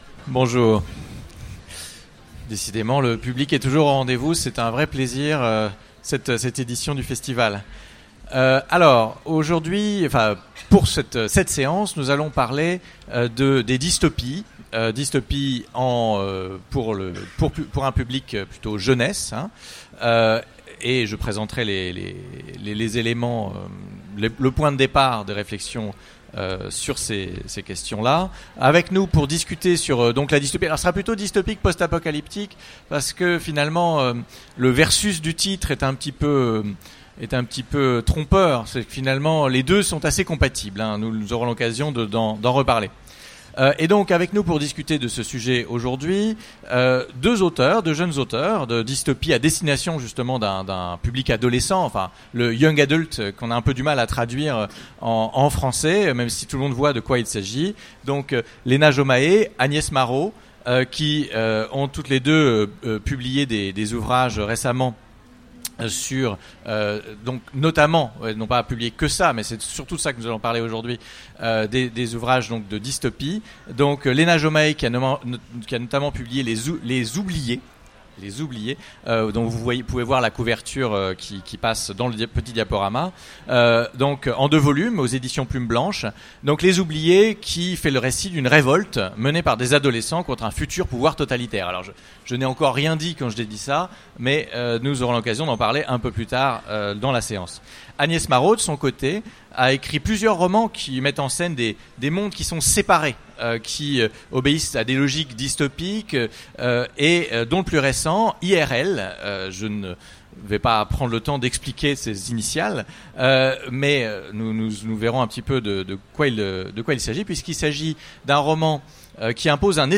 Utopiales 2016 : Conférence Dystopie vs post-apocalyptique : mécanismes d’un succès jeunesse